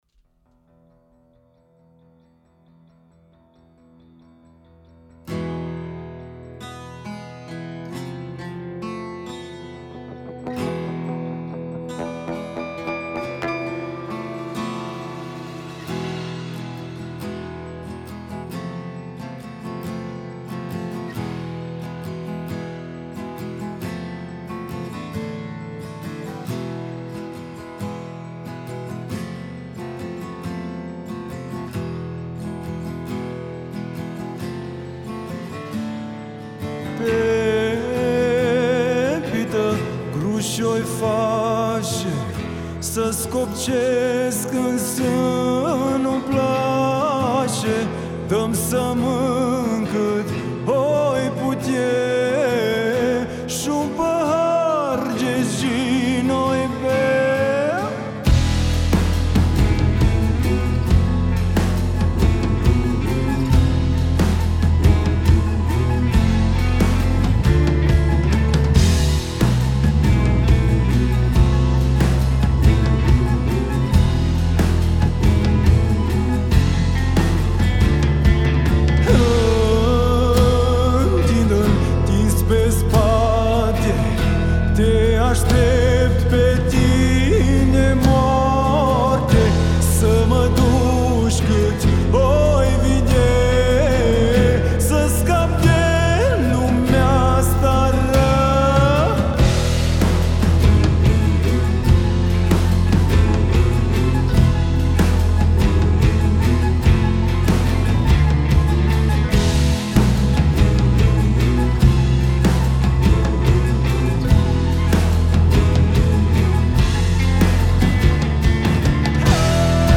prin versuri nostalgice și o linie melodică molipsitoare.